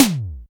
IMPCTTOM HI.wav